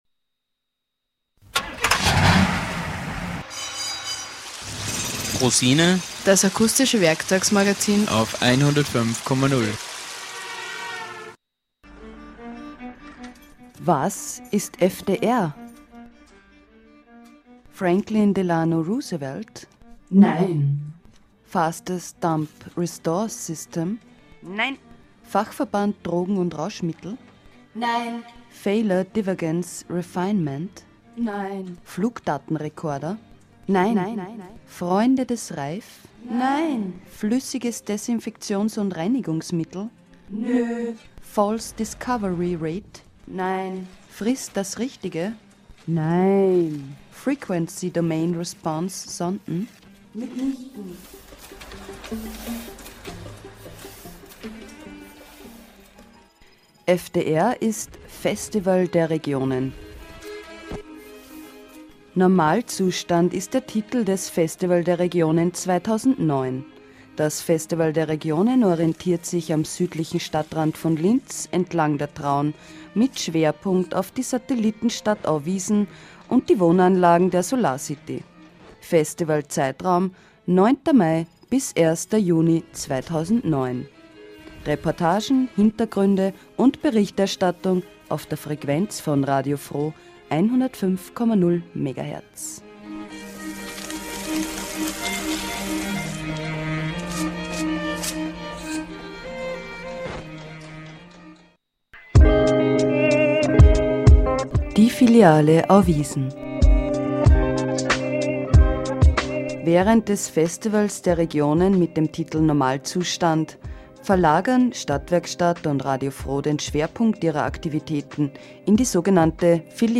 Wir haben versucht die wenigen Leute auf der Straße danach zu fragen und haben oft mehr Antworten bekommen, als wir gefragt haben.